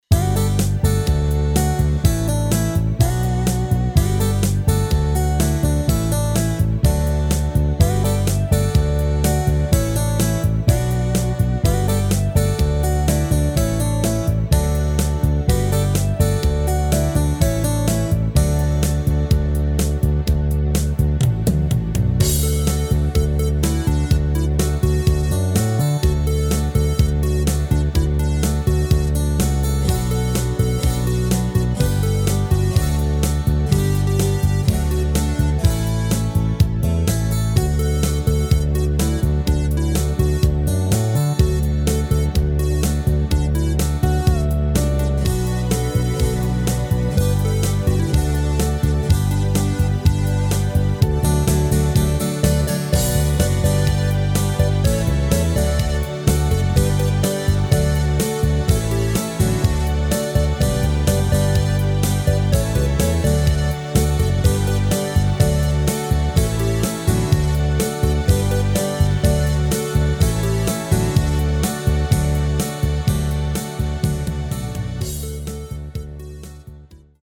Rubrika: Pop, rock, beat
HUDEBNÍ PODKLADY V AUDIO A VIDEO SOUBORECH